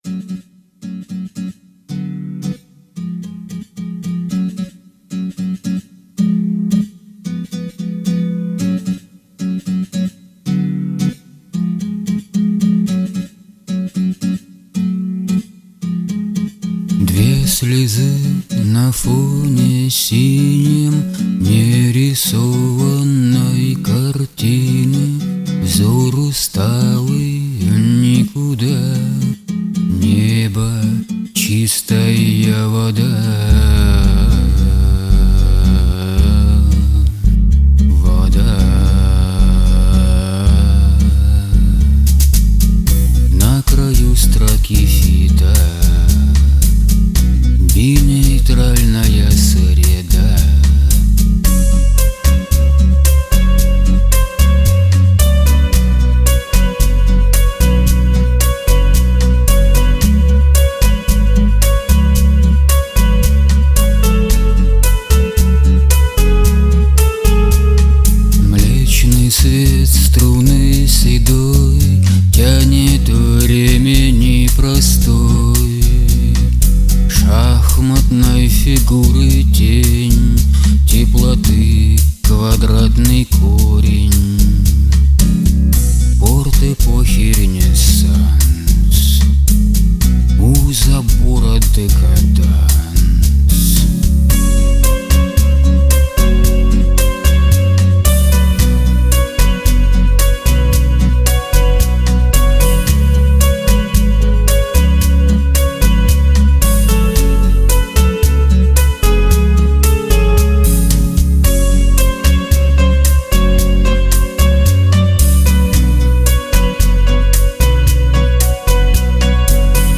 • Жанр: Поп
Попс